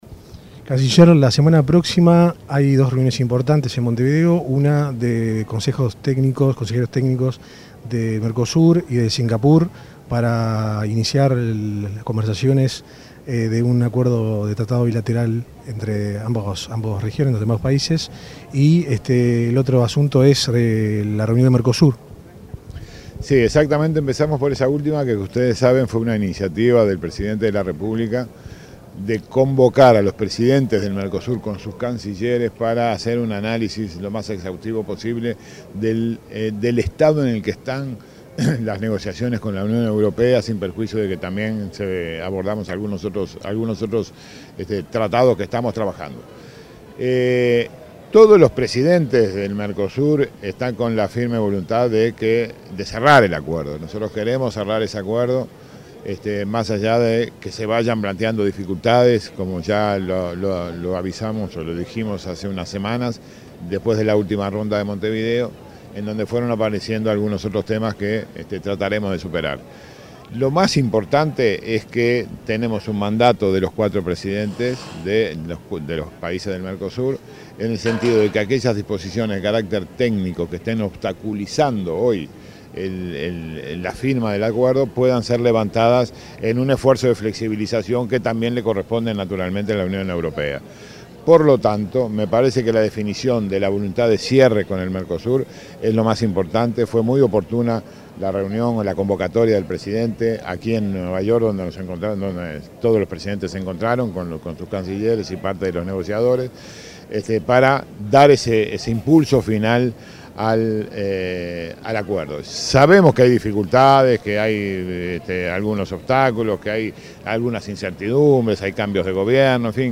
En diálogo con la Secretaría de Comunicación, en Nueva York, el canciller Rodolfo Nin Novoa destacó las próximas reuniones que se llevarán a cabo en Montevideo: la de cancilleres del Mercosur, en la que se seguirá avanzando en el tratado con la Unión Europea, la de consejeros técnicos del Mercosur, con representantes de Singapur, y otra con Canadá, sobre un acuerdo bilateral de comercio y servicios.